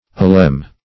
Search Result for " alem" : The Collaborative International Dictionary of English v.0.48: Alem \Al"em\, n. [Turk.